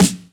Boom-Bap Snare 78.wav